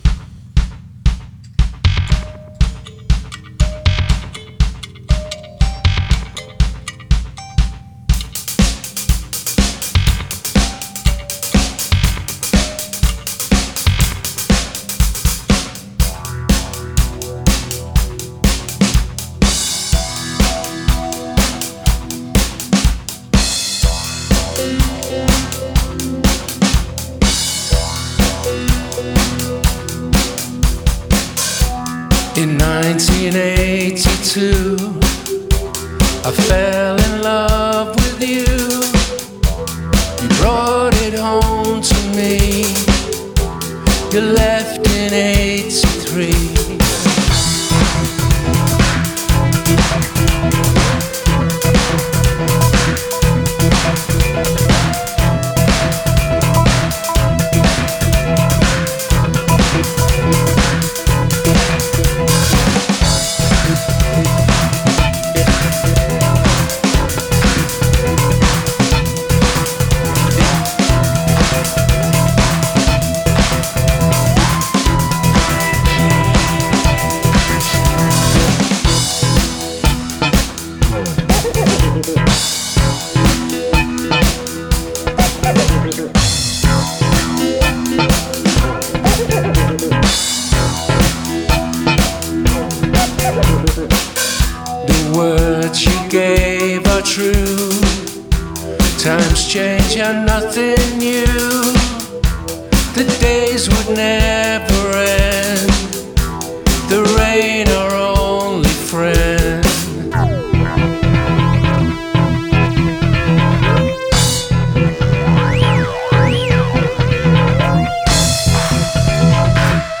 Recorded and broadcast live on April 12, 2023
Funk is alive and well and bustin’ moves everywhere.